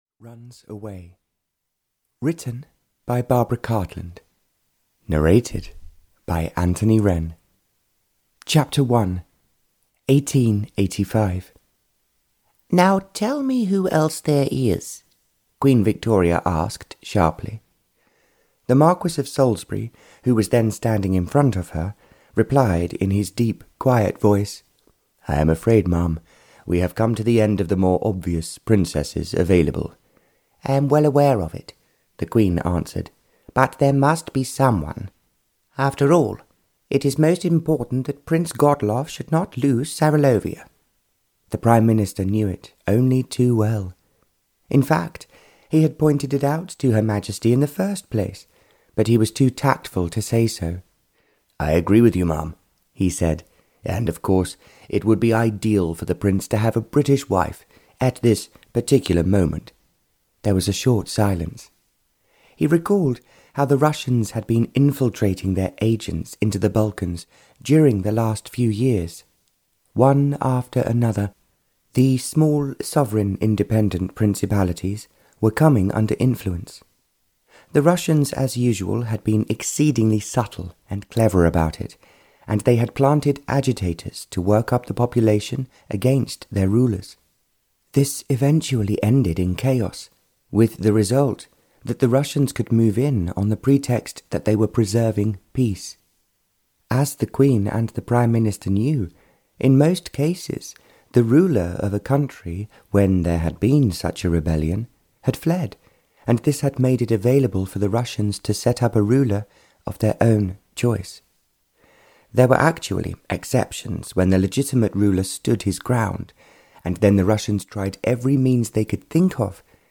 A Princess Runs Away (EN) audiokniha
Ukázka z knihy